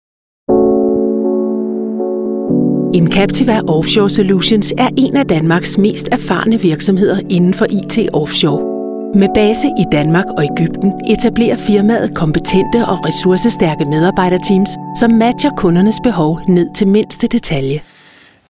Experienced female danish voiceover with warm, thrustworthy voice with a natural smile in it
Sprechprobe: Industrie (Muttersprache):
I specialize in accent-free Danish voiceover for all media and have my own professional recordingstudio.